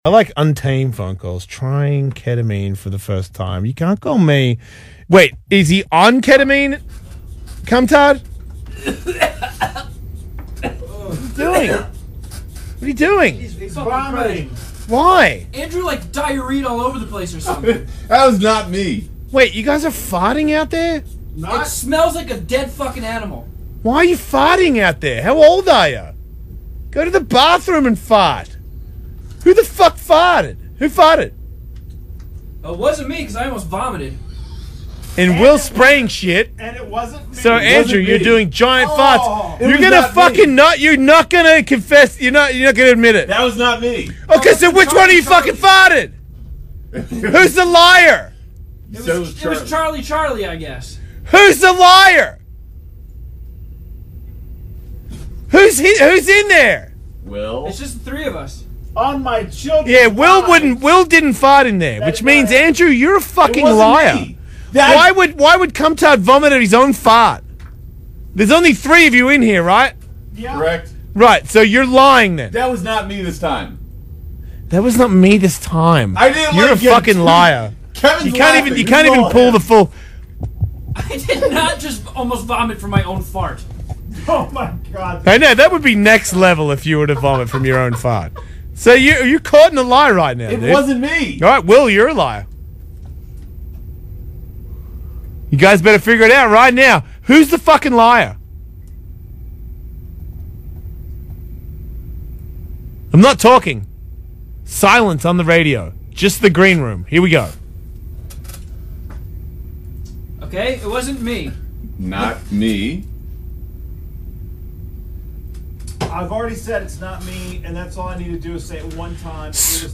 Audio of the incident (Fartio tape): Edited for time, removing only off-topic discussion and stupid as shit callers.